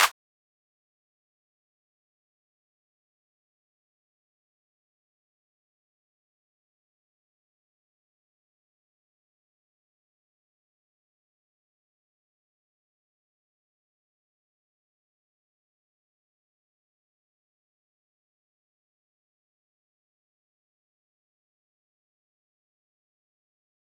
Metro Clap 2.wav